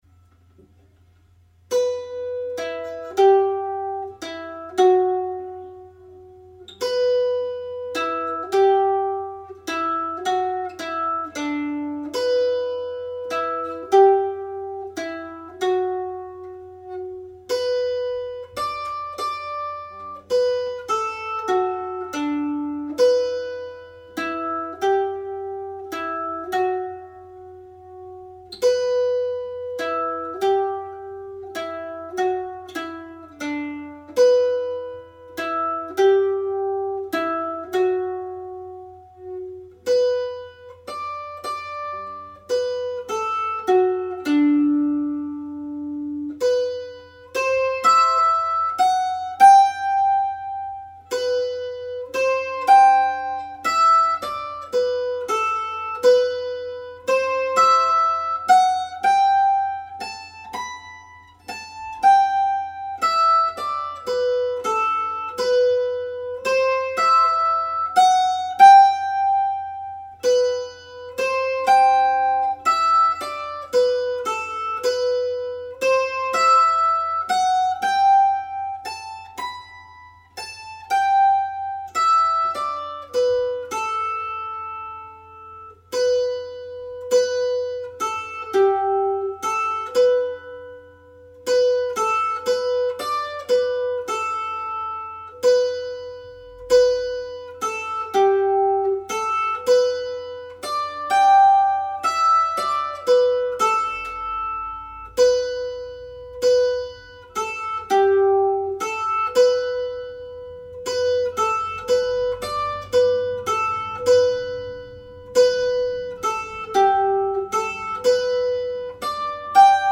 Slip jig (E Minor)
played slowly
The tune is played in the key of E minor which gives it a powerful mystical sound.